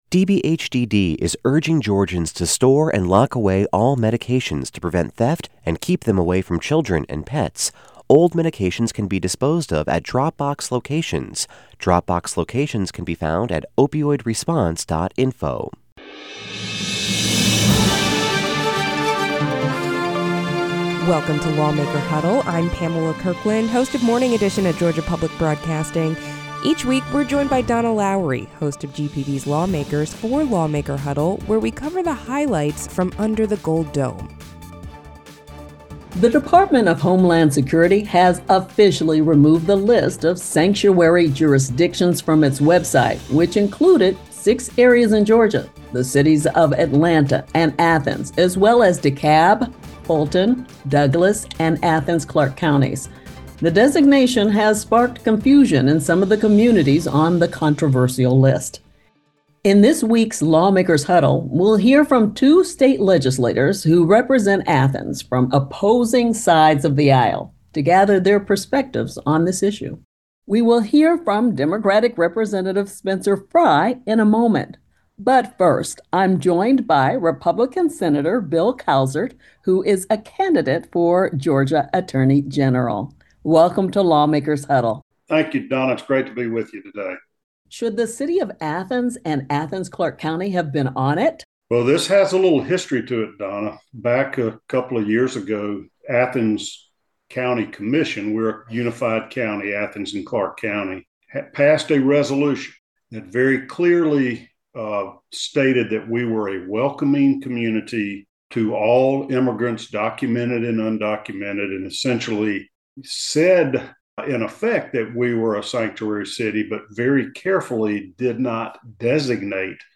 This episode features a conversation with two Athens lawmakers who share opposing views on immigration policy and the sanctuary jurisdiction label. They discuss the impact of recent legislation, local law enforcement cooperation with federal authorities, and proposals for immigration reform in Georgia.…